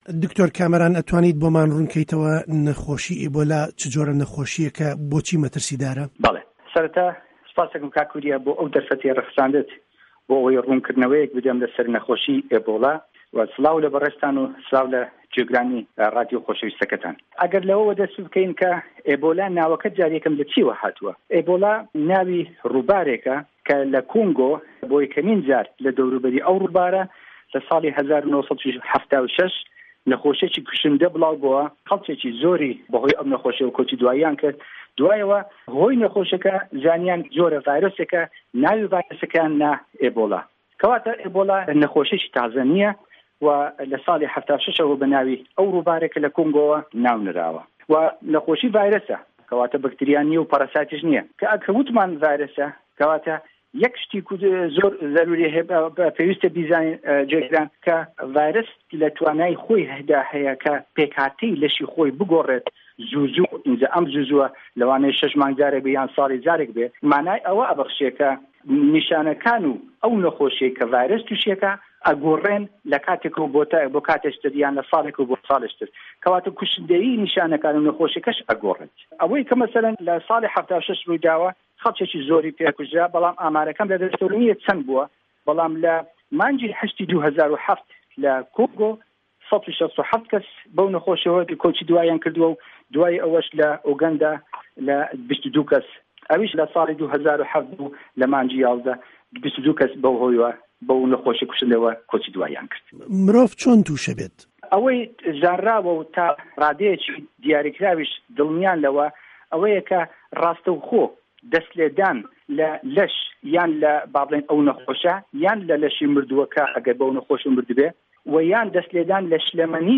جیهان - گفتوگۆکان